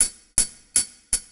Hi Hat 02.wav